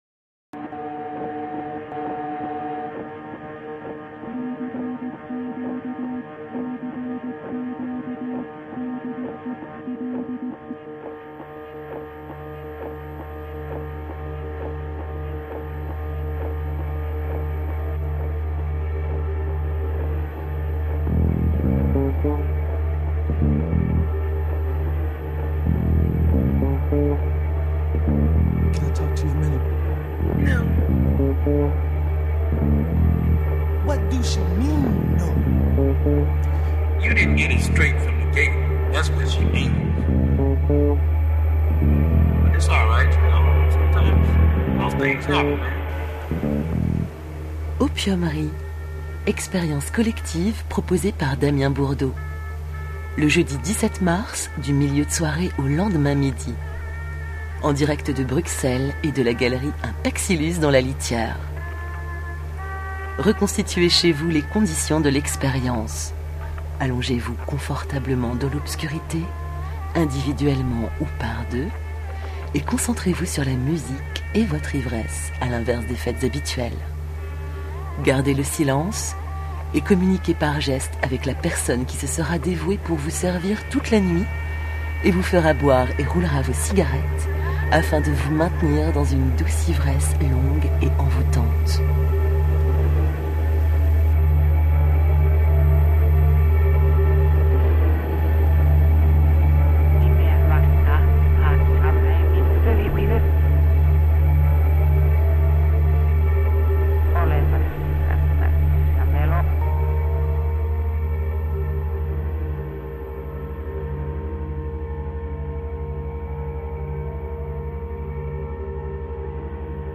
Annonce radio de opiumerie